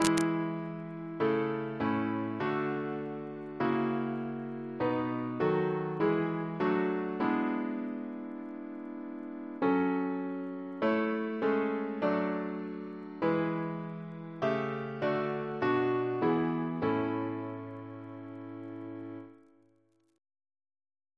Double chant in A Composer: Henry G. Ley (1887-1962) Reference psalters: ACB: 182